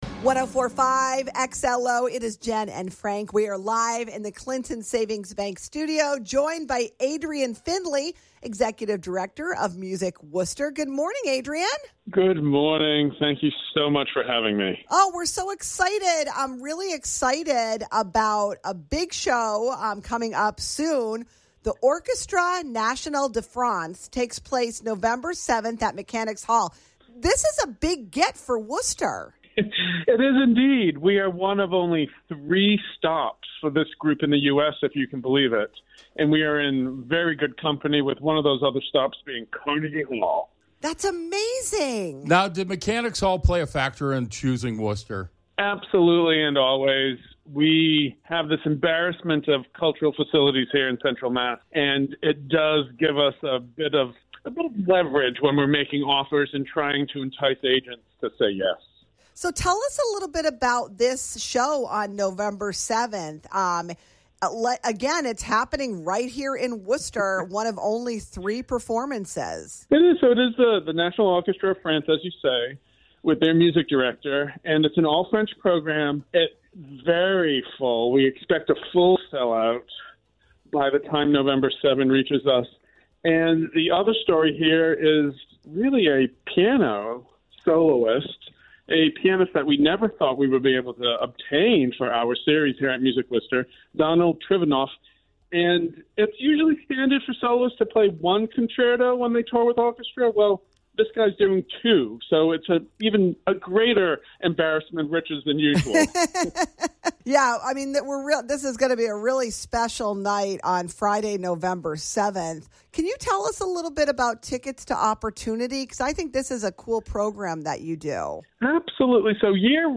WXLO Interview